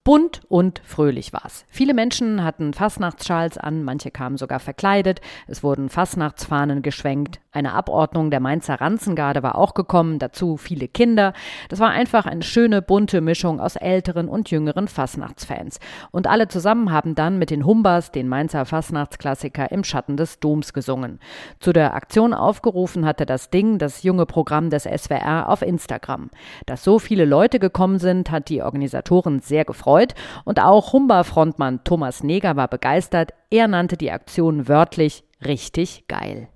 "Und Gott Jokus singt mit uns ein Halleluja": Zur Mainzer Fastnacht 2026 haben hunderte Menschen gemeinsam mit den Humbas vor dem Mainzer Dom gesungen.
Mehrere hundert Menschen haben sich am Sonntag am Liebfrauenplatz in Mainz in Fastnachtsstimmung gesungen.
Quasi im Schatten des Doms sangen dann alle gemeinsam "Im Schatten des Doms" - die Fastnachtshymne von Mainz.